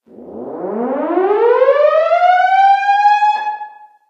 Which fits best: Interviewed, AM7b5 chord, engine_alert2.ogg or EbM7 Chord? engine_alert2.ogg